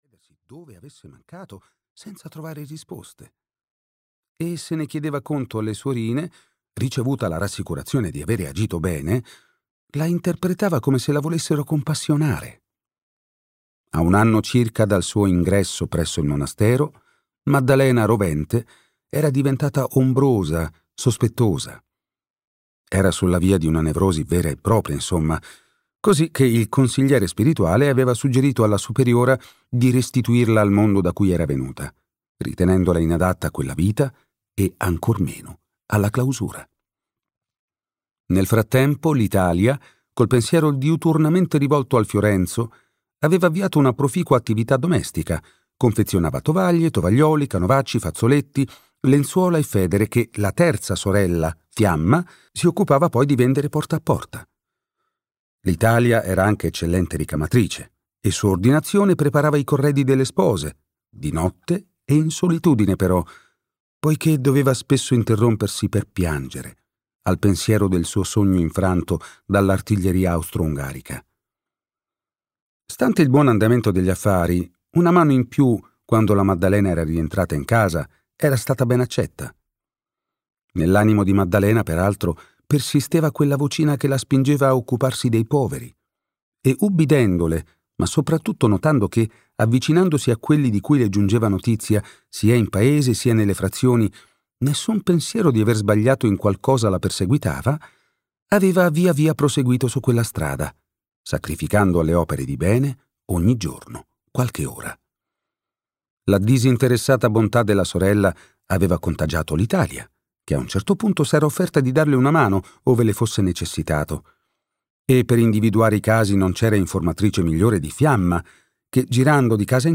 "Un bello scherzo" di Andrea Vitali - Audiolibro digitale - AUDIOLIBRI LIQUIDI - Il Libraio